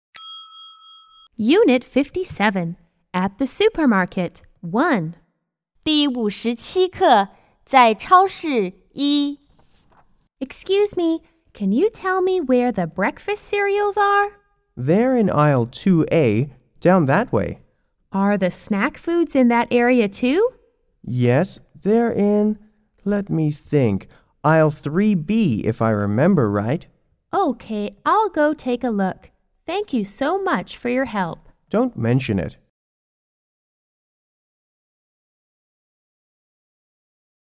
S= Shopper C= Clerk